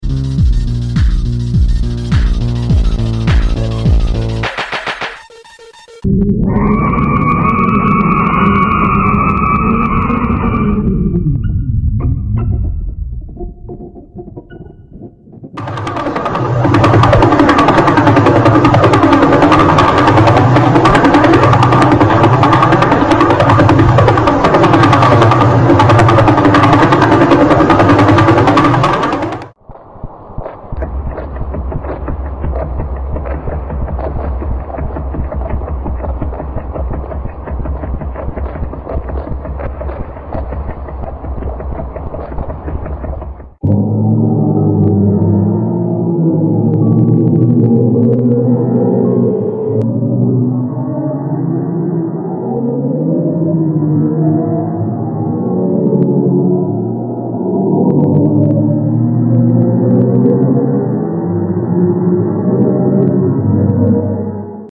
There is no sound in a vacuum but scientist are able to analyze and create these thanks to the particles emitted by these cosmic giants.